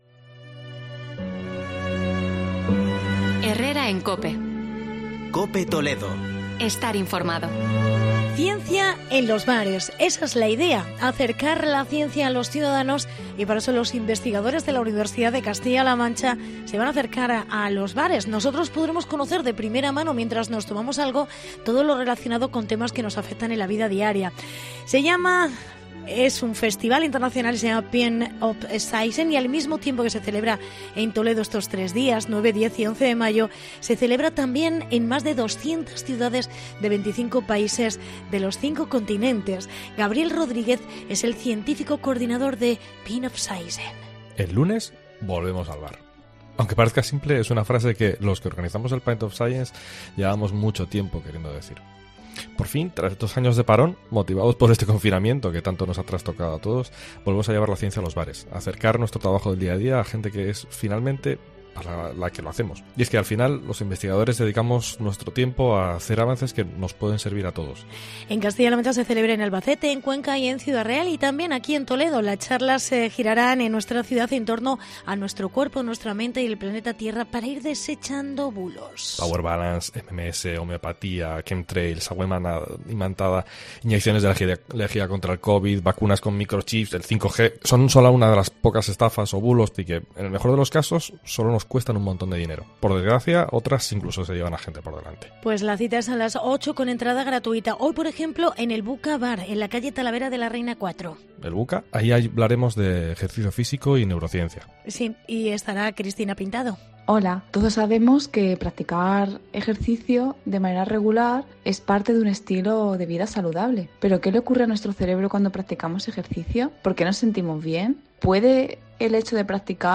Reportaje Pint of Science en Toledo